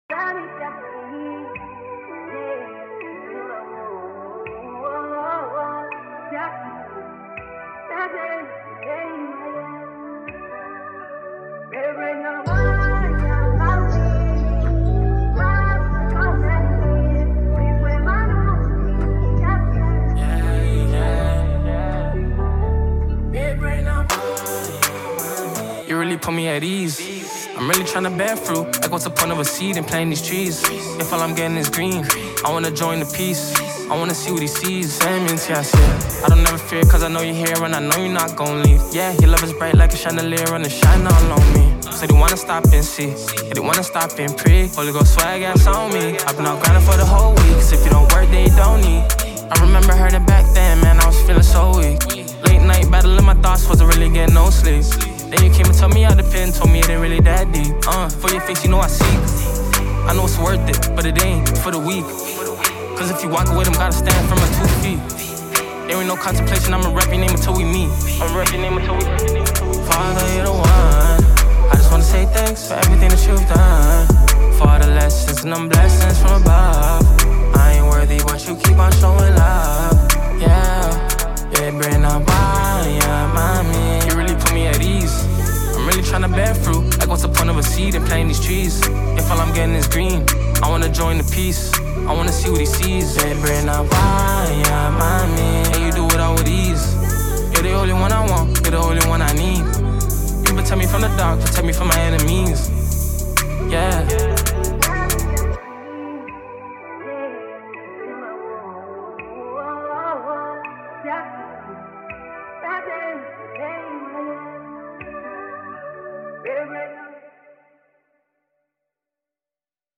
Sensational rapper
a song sampled